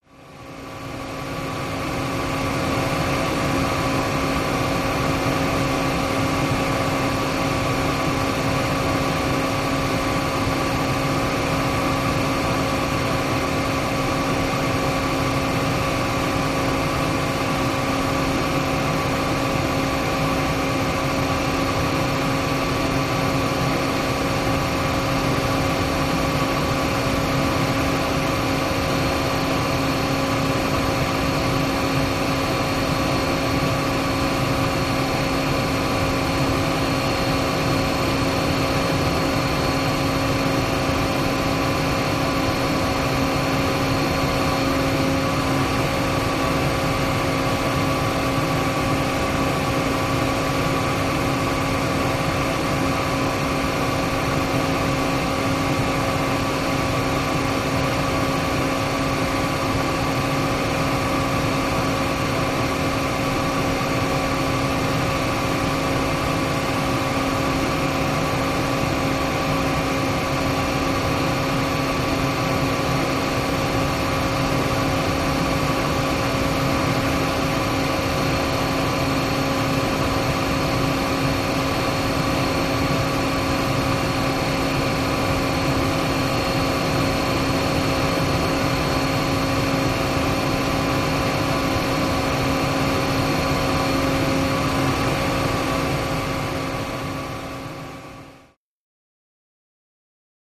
Industrial Fans Blowers Steady